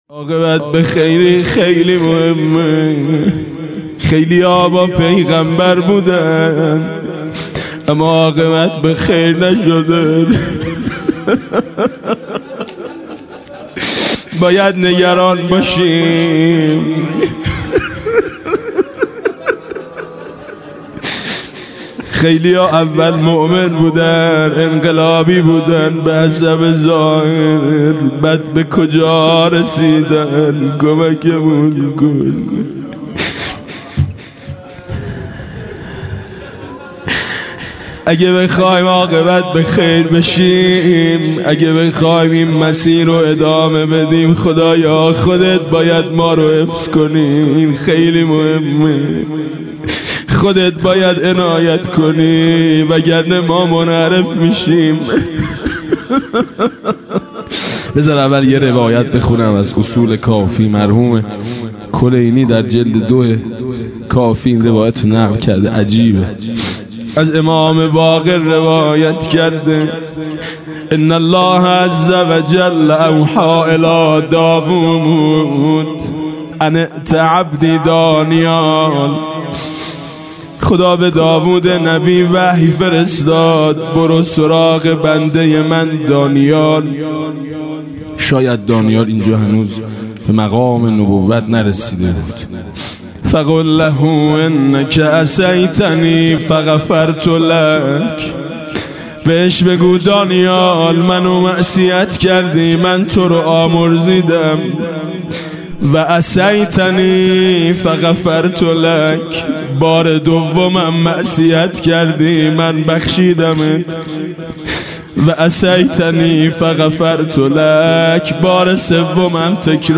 مناسبت : شب سوم رمضان
مداح : میثم مطیعی قالب : مناجات